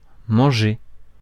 [mɑ̃ʒe] 'to eat' See French phonology